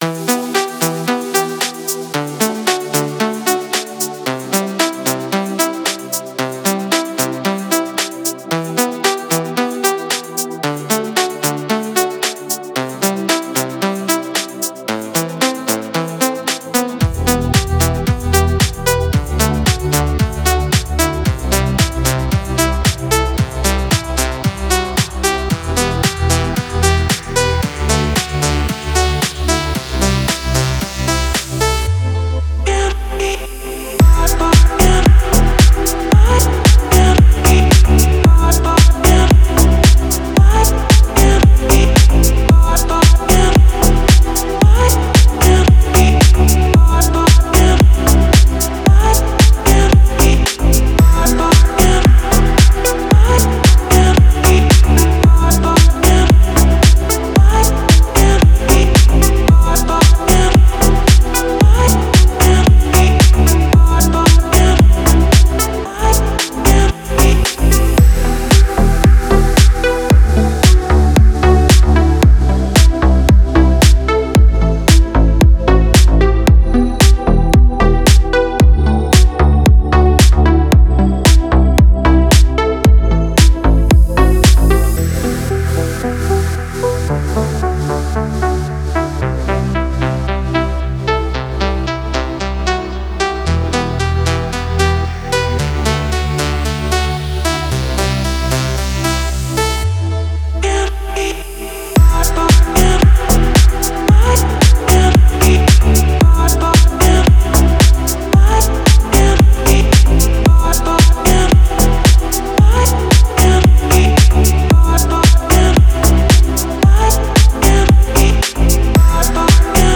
1901___Deep_House__S.mp3